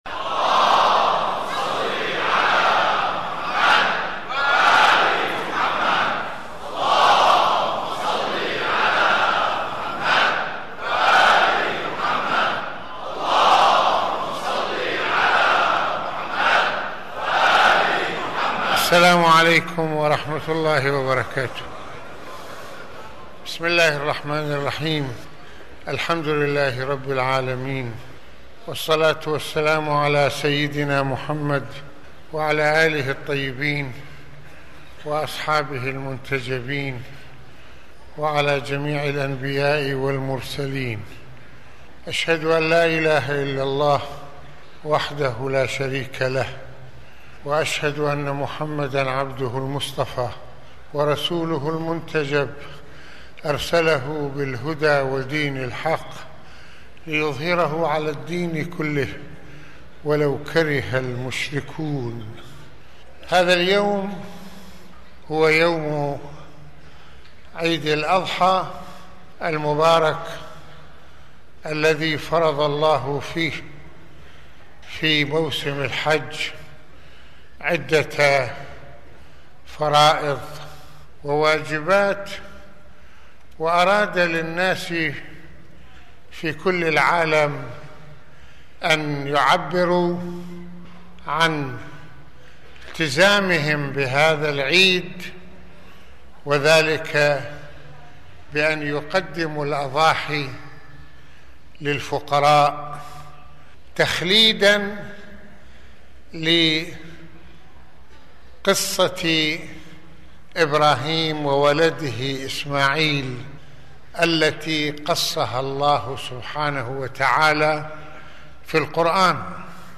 - يتحدث المرجع فضل الله (رض) في هذه المحاضرة عن معاني عيد الأضحى المبارك، ويعرّج على قصة إبراهيم الخليل(ع) وذبحه لولده إسماعيل(ع) وتصديقه للرؤيا، والدروس التي أراد الله للإنسان أن يستلهمها من تلك التجربة...